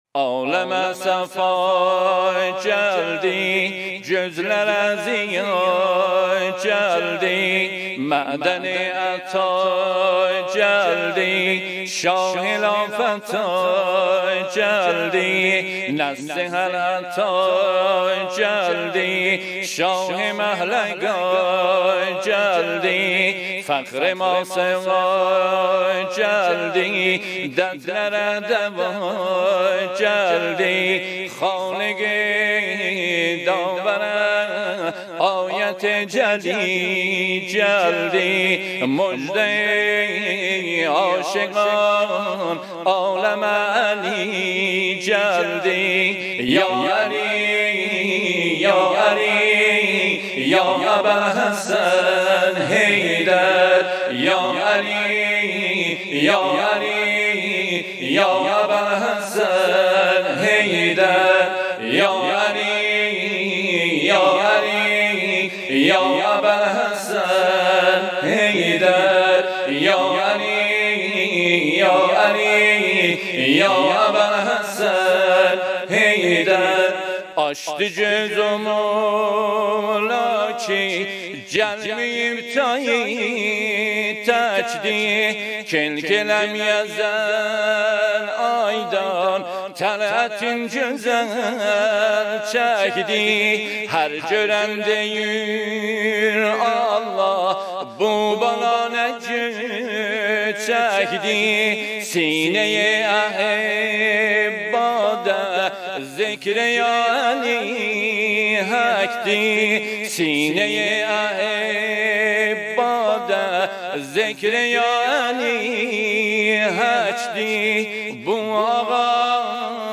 مولودی آذری مولودی ترکی